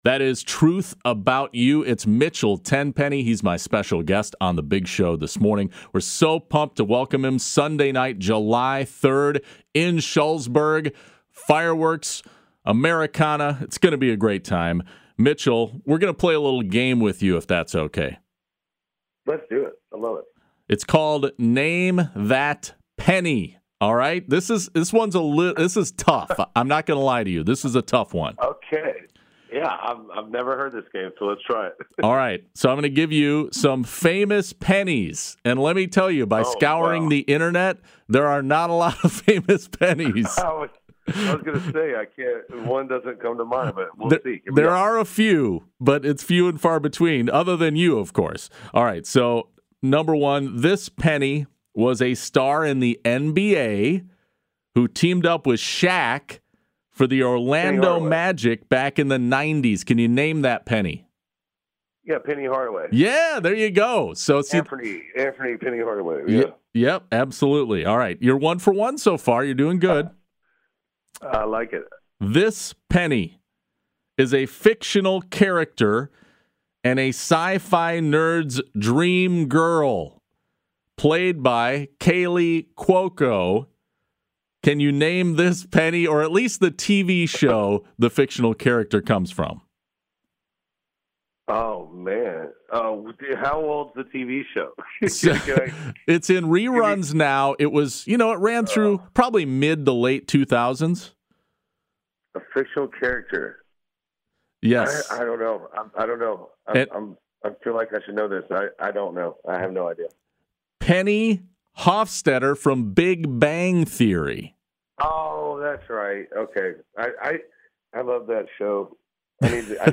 Mitchell Tenpenny LIVE on The Big Show 6/24/22